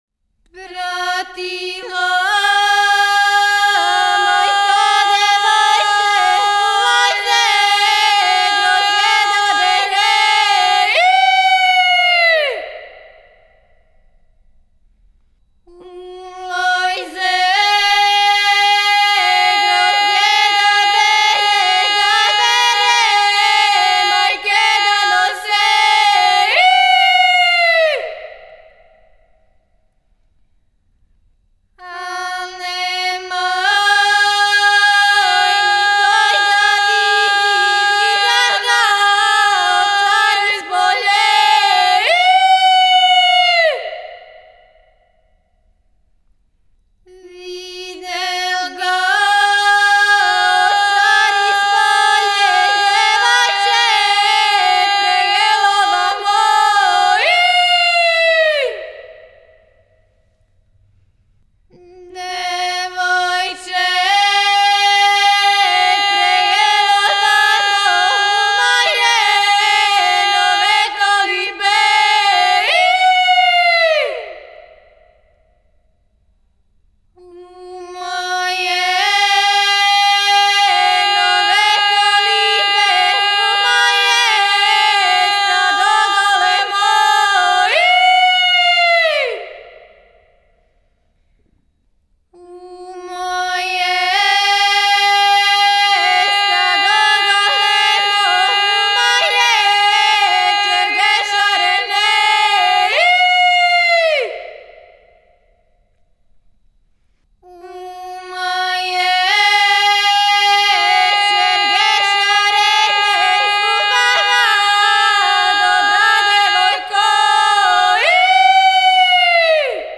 Порекло песме: Село Доња Студена код Ниша Начин певања: На глас. Напомена: "Гројзоберска" песма.